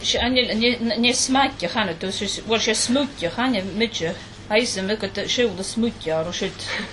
Those I spoke to in Eriskay who recognised the word pronounced it smuig.